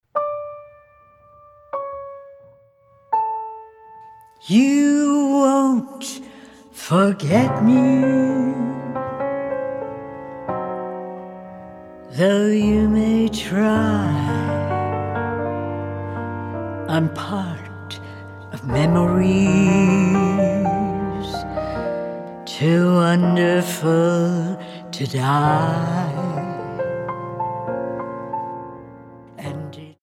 with a string arrangement